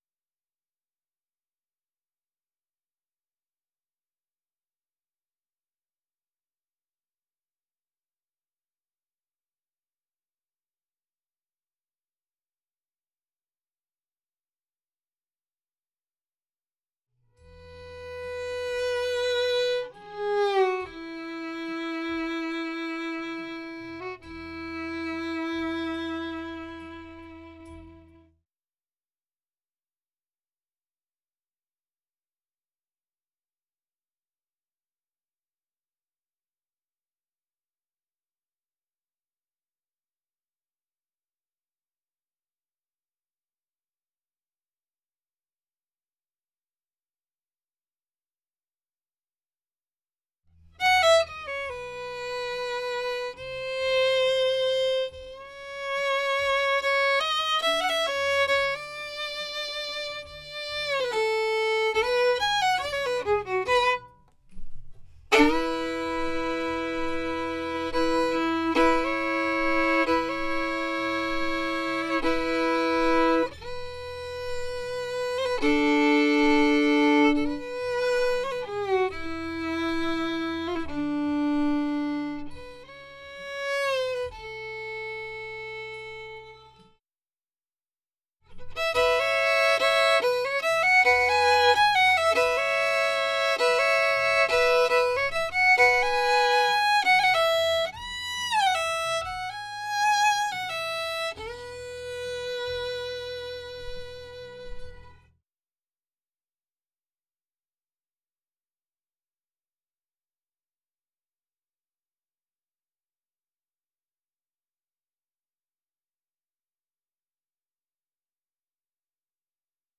Fiddle-Melt.wav